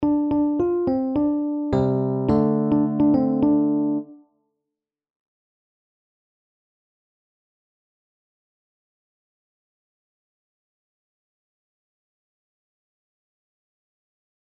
Kleine terts